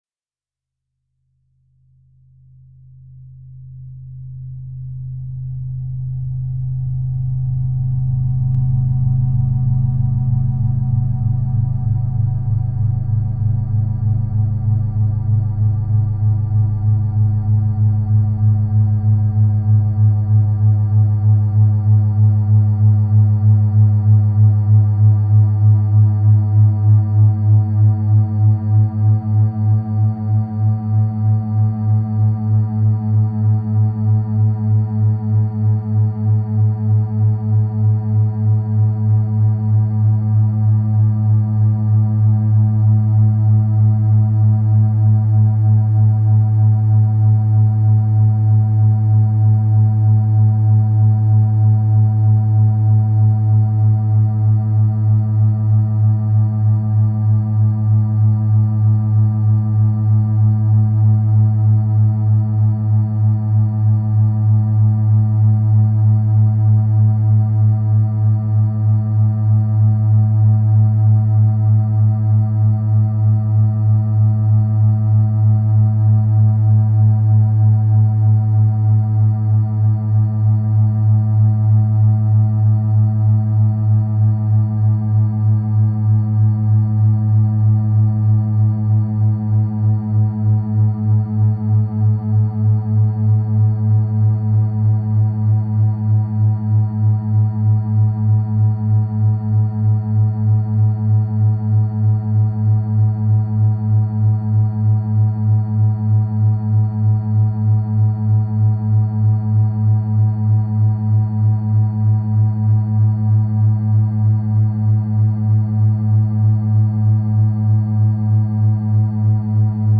drone discipline